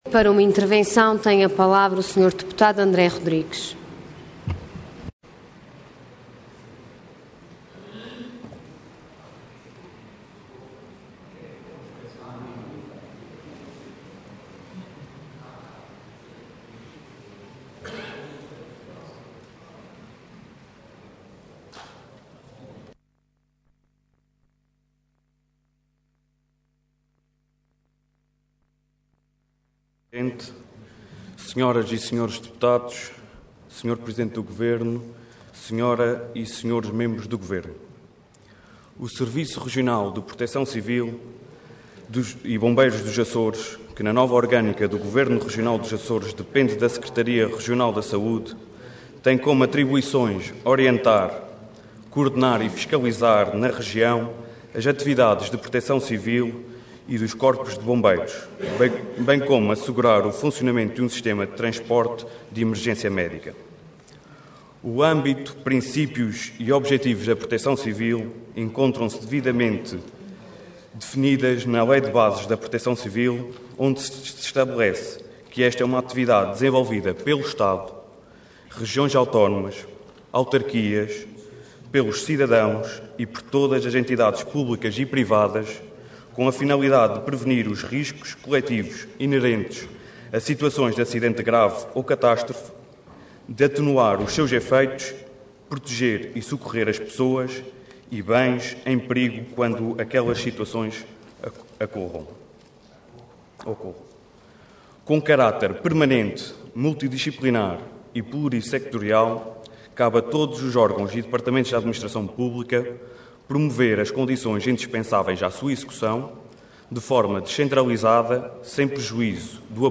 Intervenção Intervenção de Tribuna Orador André Rodrigues Cargo Deputado Entidade PS